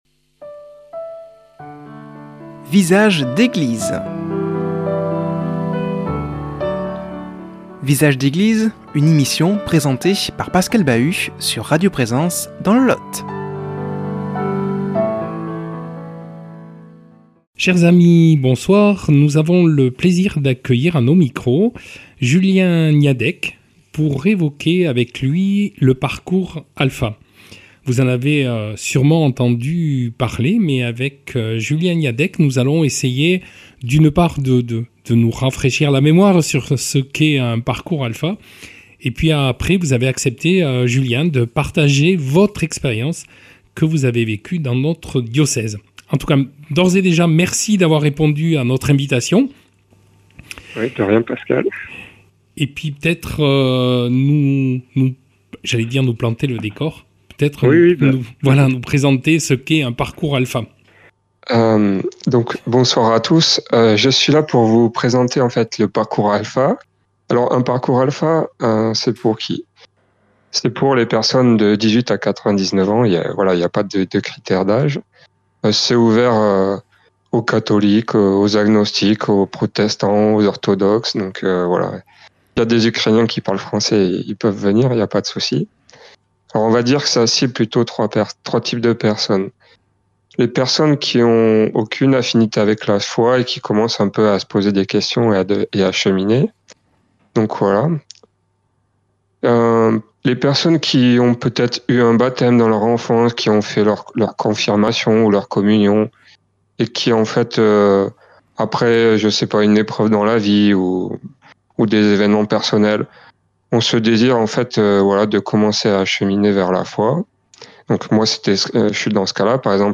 reçoit comme invité par téléphone